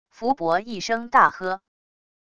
福伯一声大喝wav音频